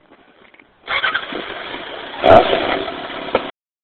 Petit quizz: A quelle moto appartiennent ces bruits?
moto_3.mp3